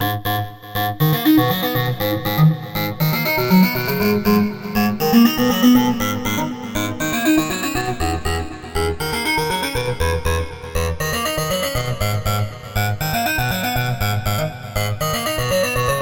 描述：为你的轨道提供3个电子低音循环。
Tag: 125 bpm Electro Loops Bass Loops 2.93 MB wav Key : C